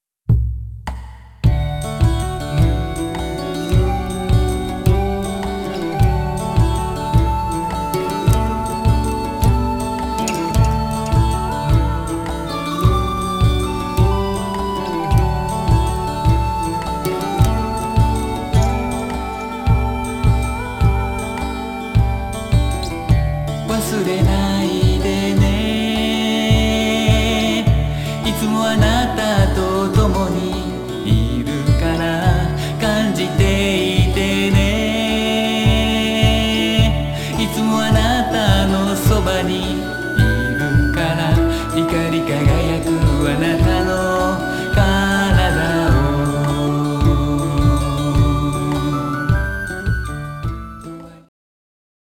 フォルクローレミュージックのバンドを組んでいます。